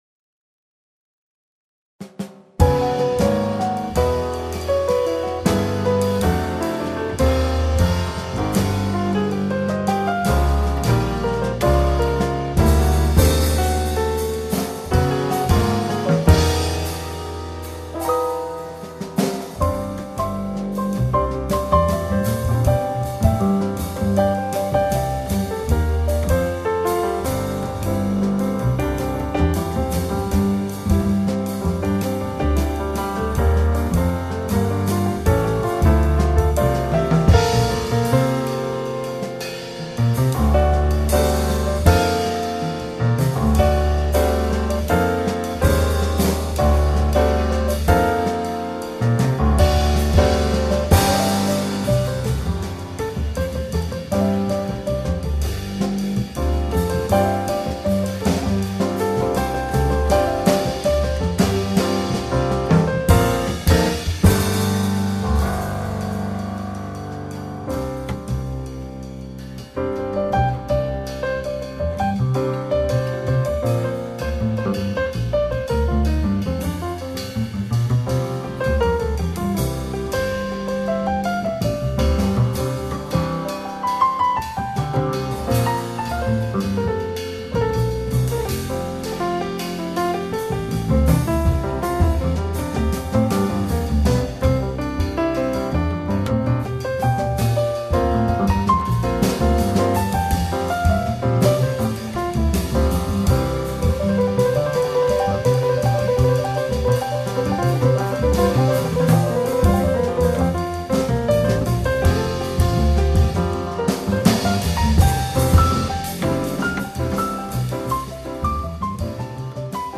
Registrato in studio il 25 Giugno 2007.
Piano
Doublebass
Drums